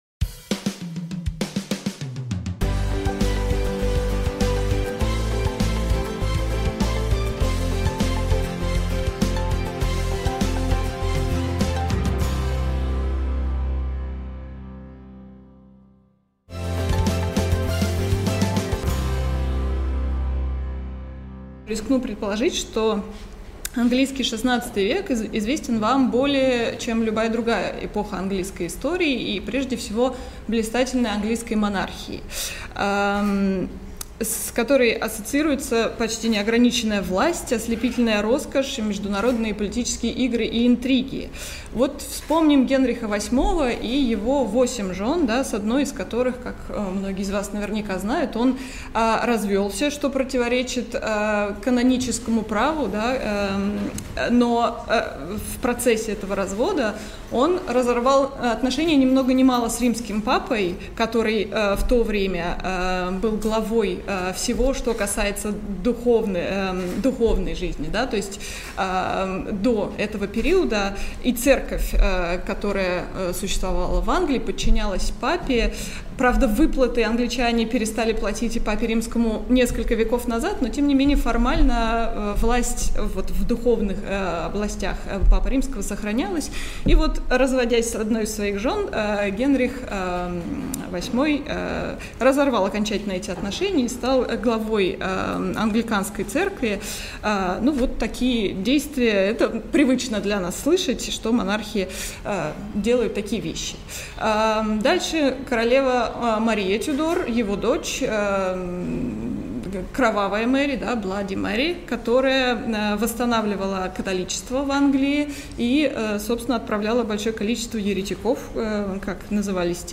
Аудиокнига Воля монарха vs логика рынка: английские правители середины XVI века на бирже Антверпена | Библиотека аудиокниг